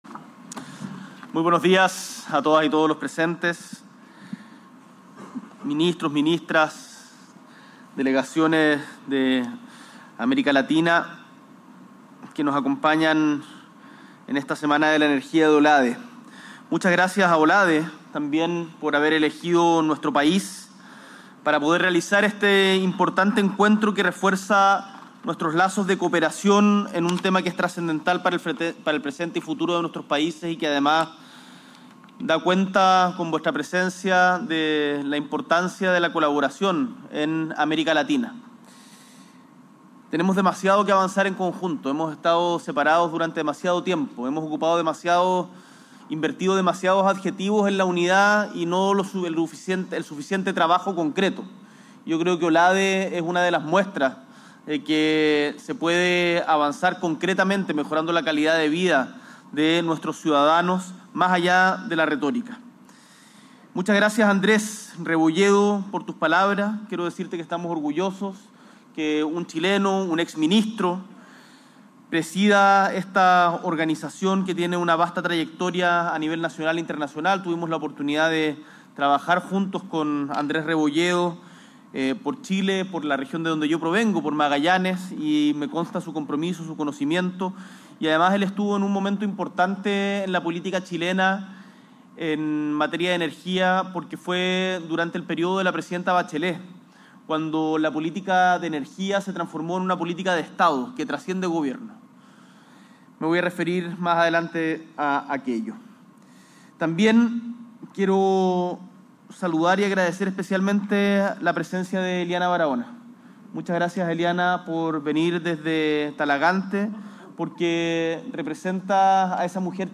S.E. el Presidente de la República, Gabriel Boric Font, encabeza la ceremonia inaugural de la Reunión de Ministros y Ministras de Energía de OLADE, en el marco de la X Semana de la Energía
Discurso